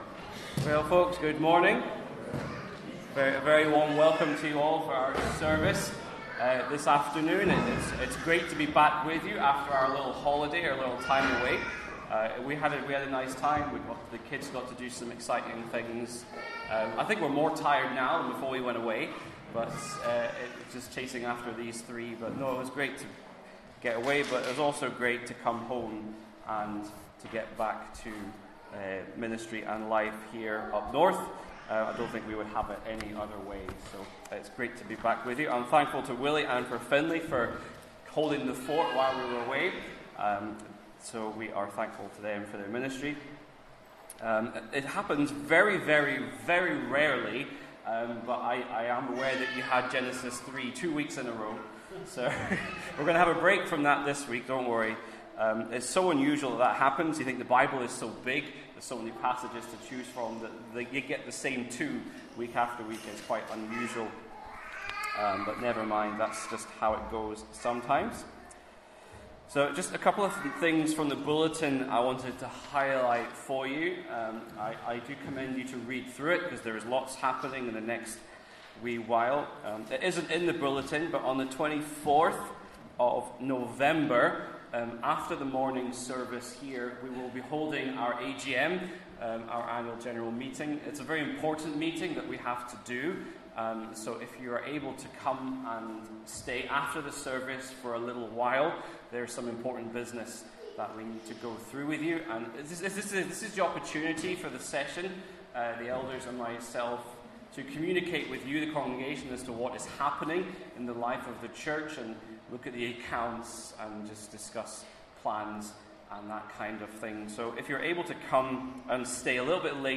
Sunday Service 3rd November